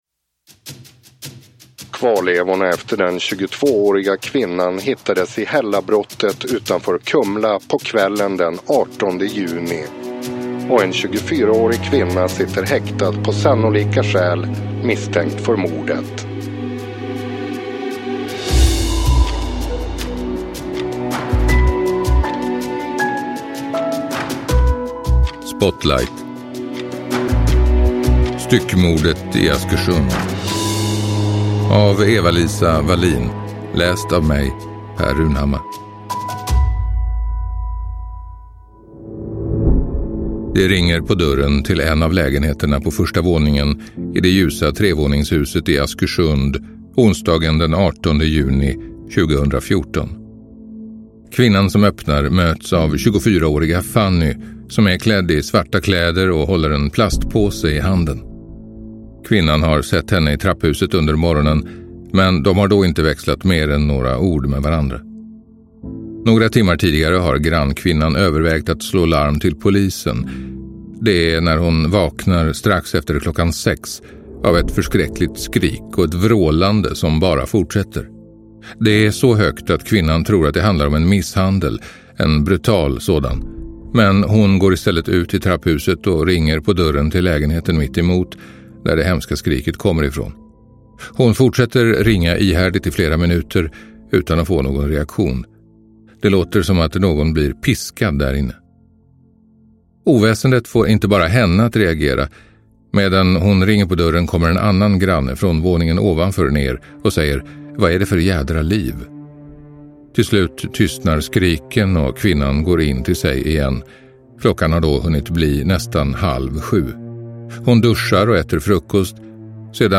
Styckmordet i Askersund – Ljudbok – Laddas ner